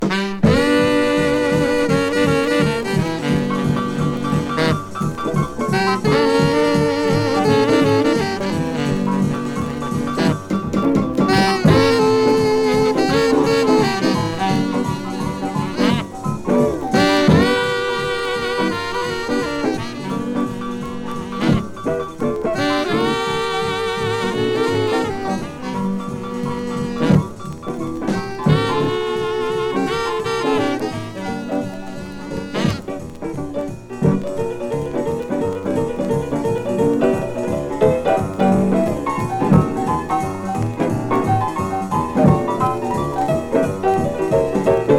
20代とは思えない貫禄ある声で、聴けば聴くほど惚れ込む素晴らしさです。
Rhythm & Blues, Ballad　UK　12inchレコード　33rpm　Mono